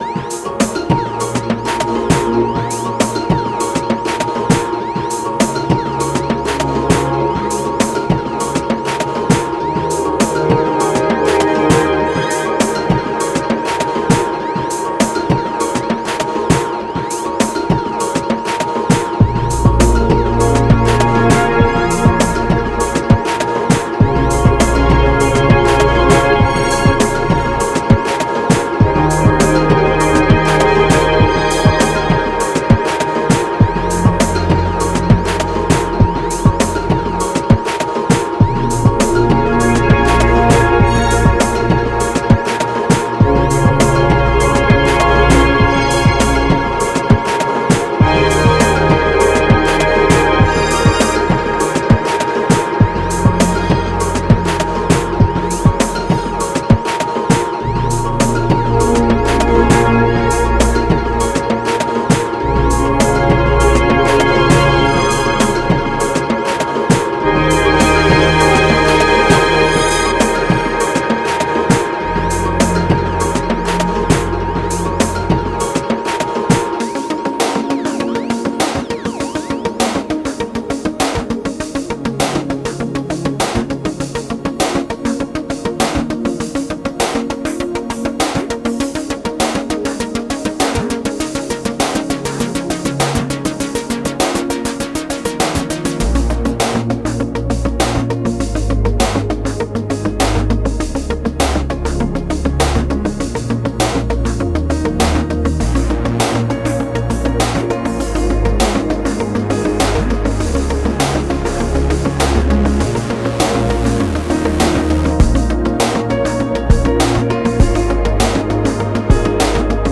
Genre:Downtempo
25 Synth Atmos Loops
09 Piano Loops
17 Percussion Loops